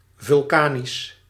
Ääntäminen
Ääntäminen Paris Tuntematon aksentti: IPA: /vɔl.ka.nik/ Haettu sana löytyi näillä lähdekielillä: ranska Käännös Ääninäyte 1. vulkanisch Suku: f .